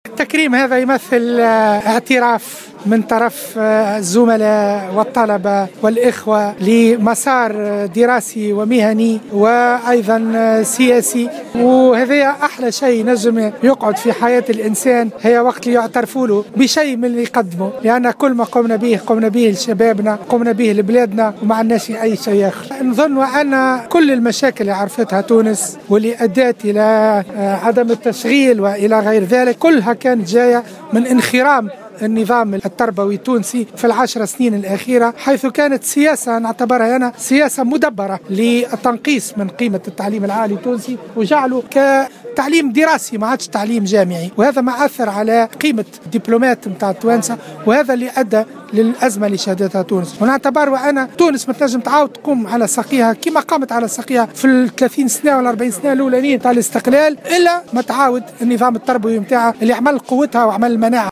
في تصريح إعلامي على هامش حفل انتظم بكلية العلوم القانونية والسياسية والاجتماعية بتونس